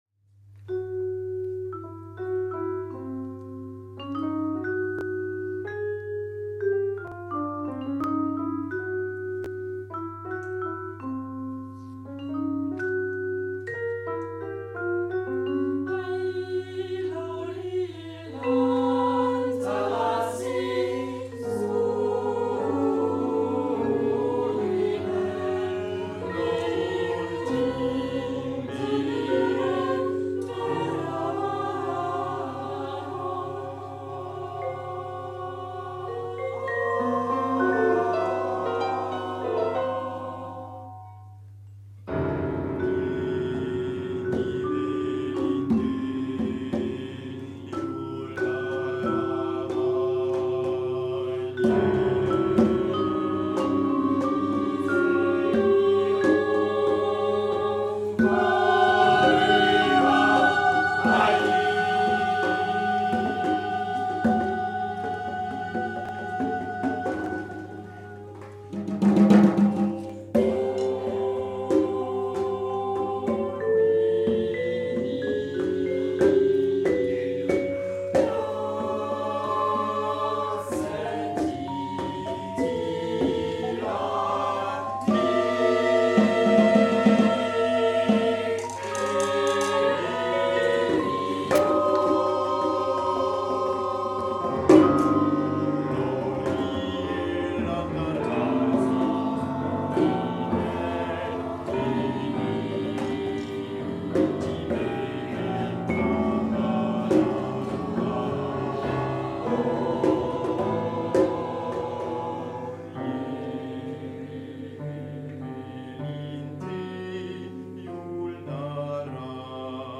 C'était pour choeur, piano et deux percussionistes.
L'enregistrement est très moyen et il y a des erreurs mais bon !